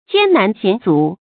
注音：ㄐㄧㄢ ㄣㄢˊ ㄒㄧㄢˇ ㄗㄨˇ
艱難險阻的讀法